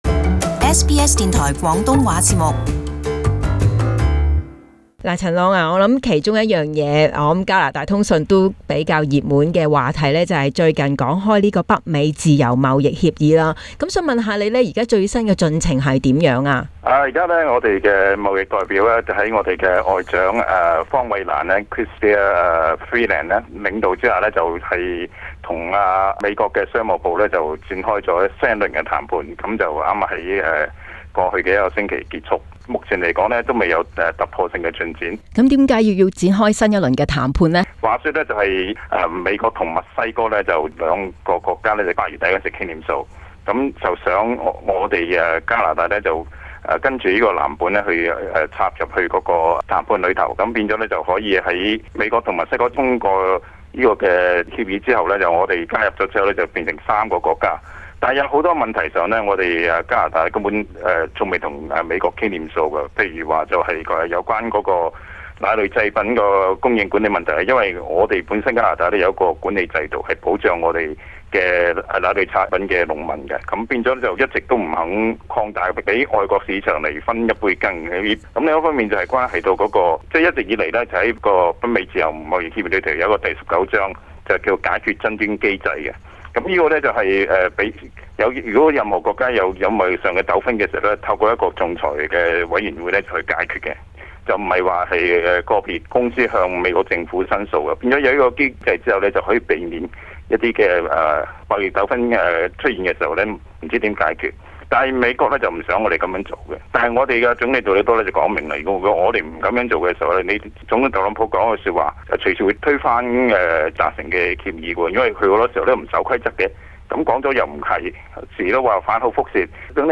【加拿大通訊】北美自由貿易協定